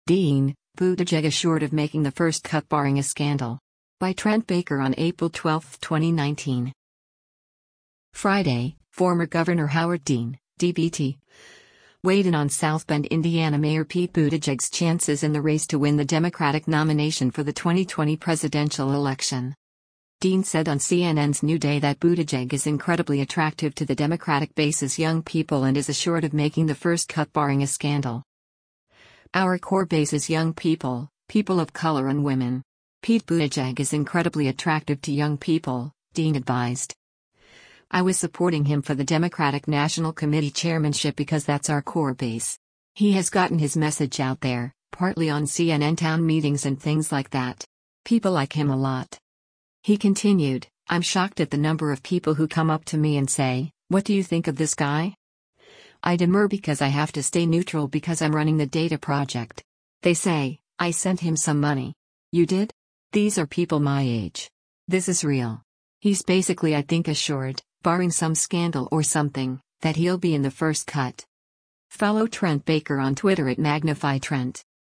Dean said on CNN’s “New Day” that Buttigieg is “incredibly attractive” to the Democratic base’s young people and is “assured” of making “the first cut” barring a scandal.